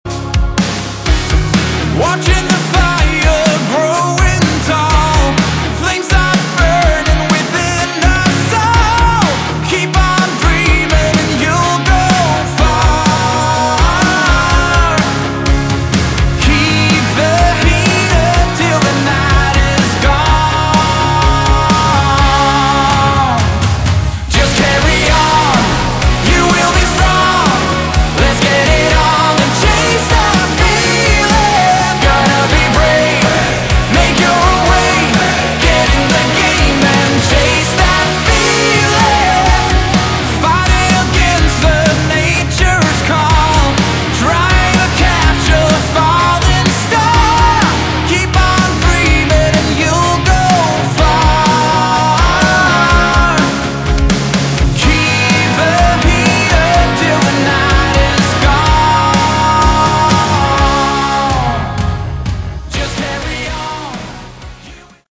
Category: AOR / Melodic Rock